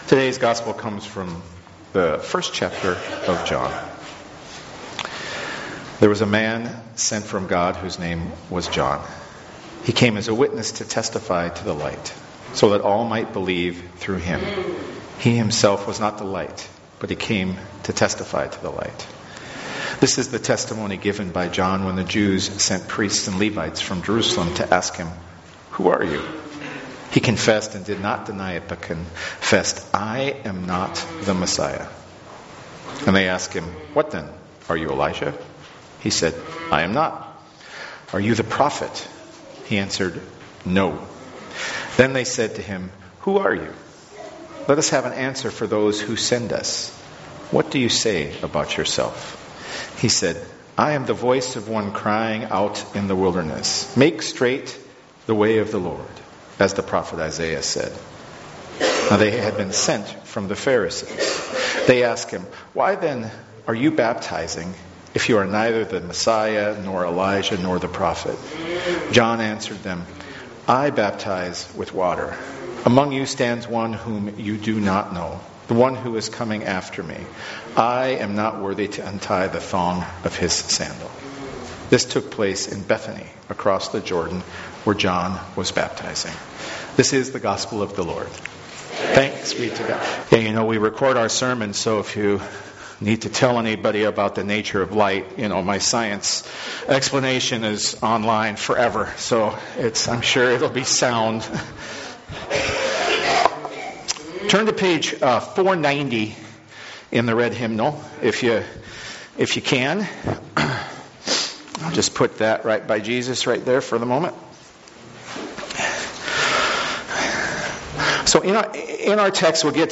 Our Gospel reading for today, the 3rd Sunday of Advent, is from John 1:6-8, 19-28, which starts off as ‘There was a man sent from God whose name was John.